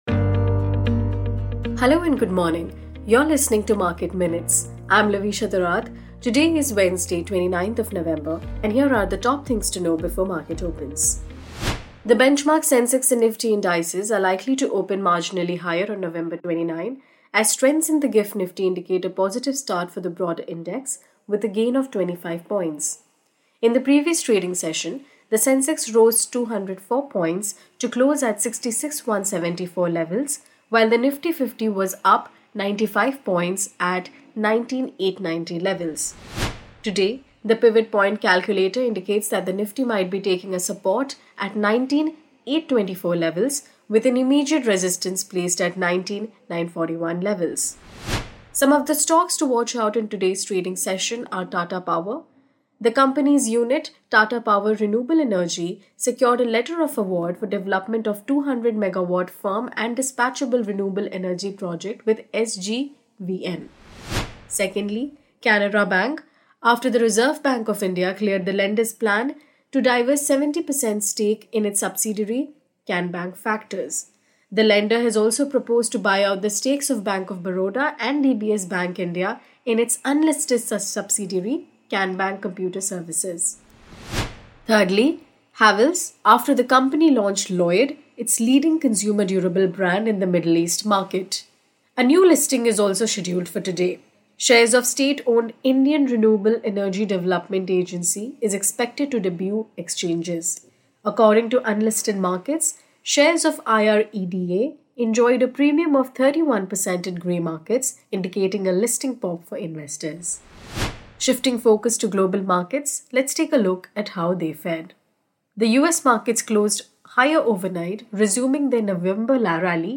Market Minutes is a morning podcast that puts the spotlight on hot stocks, key data points, and developing trends.